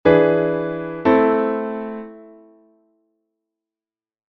21_perfecta_en_la_m.mp3